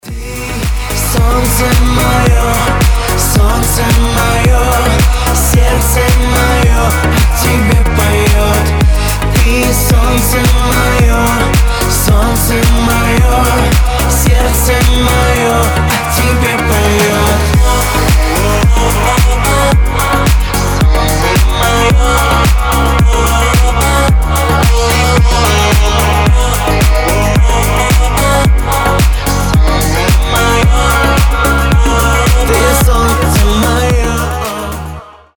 • Качество: 320, Stereo
поп
мужской вокал
deep house